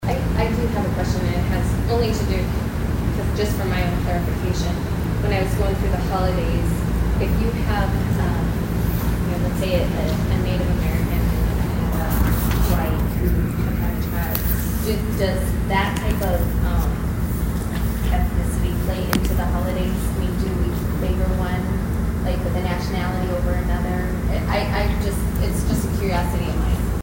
Representative Brandei Schaefbauer wanted to clarified the issue of holidays based on different cultural backgrounds.